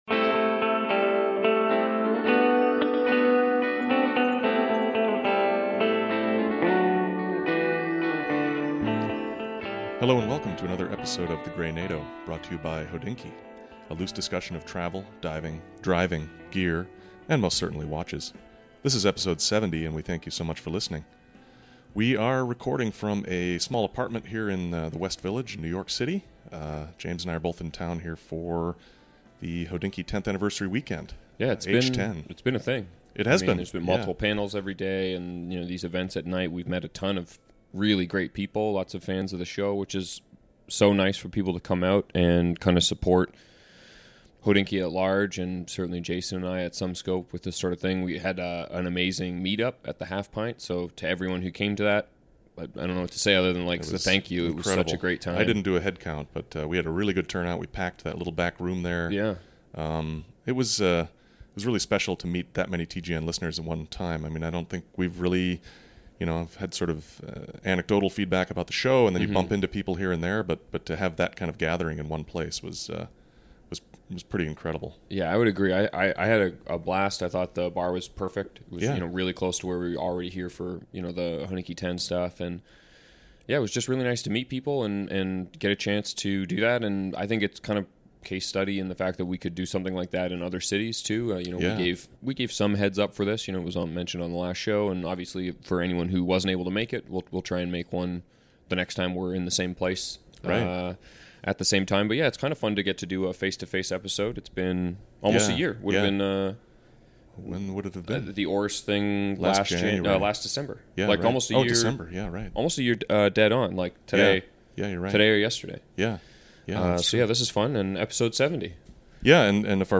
We are recording from a small apartment here in the West Village in New York City.